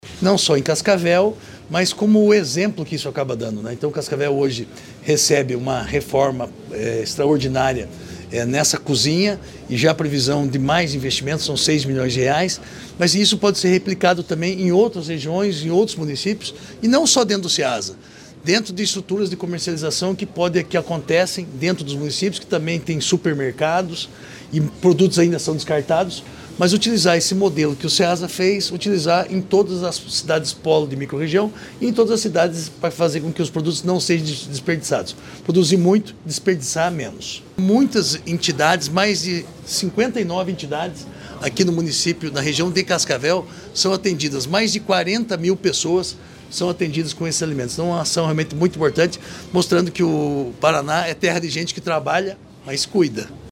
Sonora do secretário da Agricultura e do Abastecimento, Márcio Nunes, sobre a modernização do Banco de Alimentos da Ceasa Cascavel